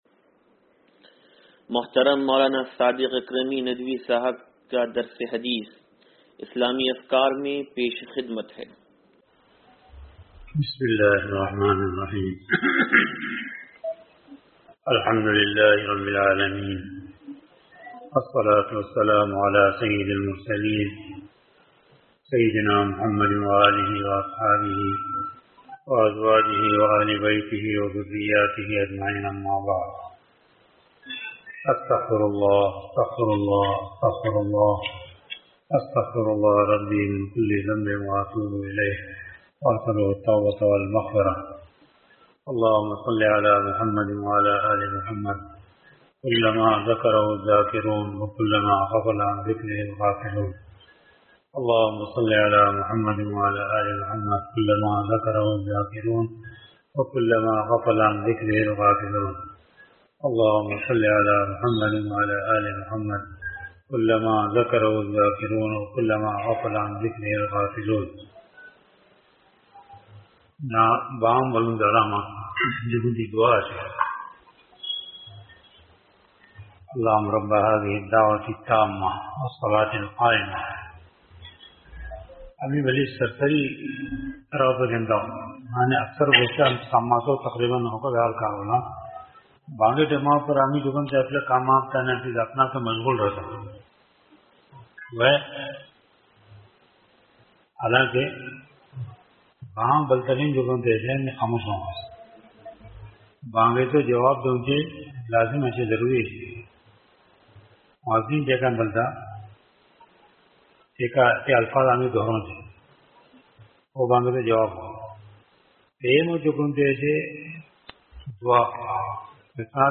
درس حدیث نمبر 0458
(تنظیم مسجد)